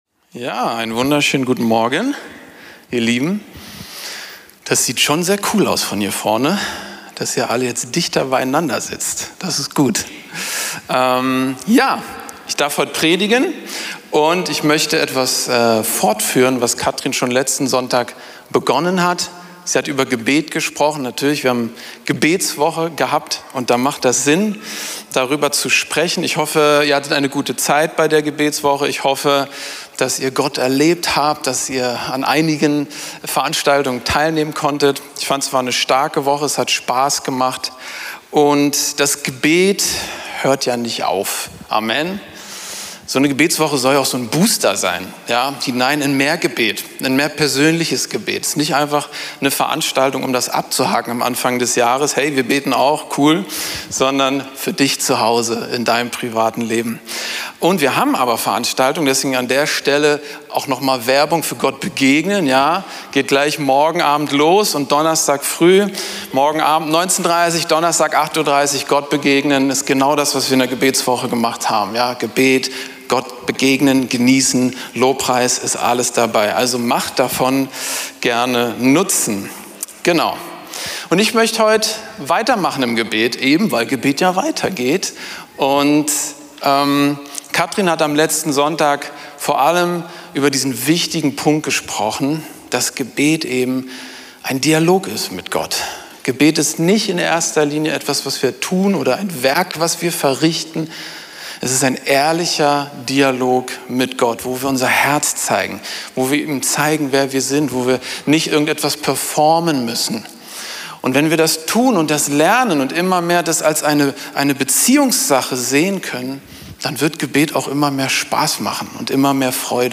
Predigten von Veranstaltungen der Gemeinde auf dem Weg, Berlin